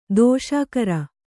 ♪ dōṣākara